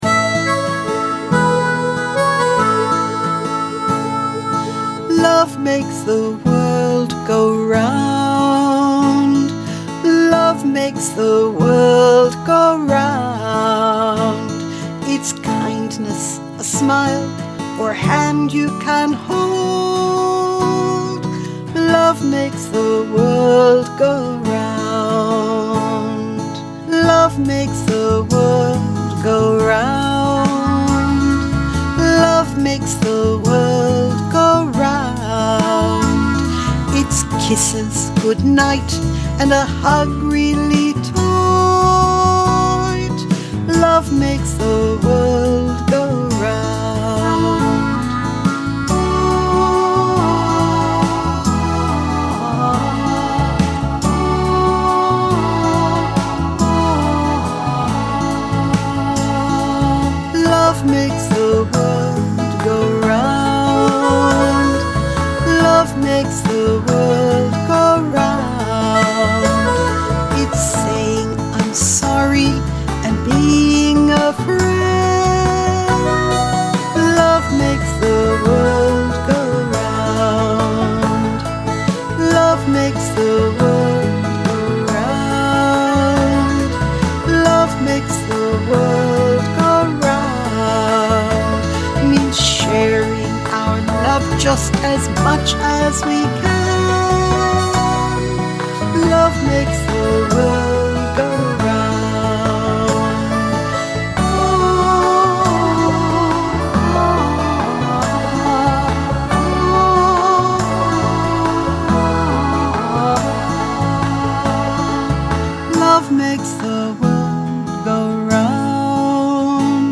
all ages!   leadsheet  lyrics    mp3 vocal